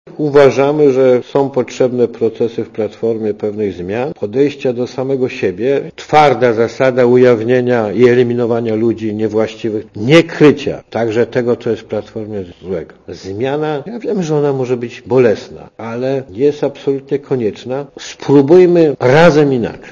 Posłuchaj Lecha Kaczyńskiego
"Oczekujemy, że będzie twarda zasada ujawniania i wyeliminowania ludzi niewłaściwych ze swoich szeregów, nie ukrywania także tego, co jest w Platformie złe" - podkreślił na konferencji prasowej lider PiS.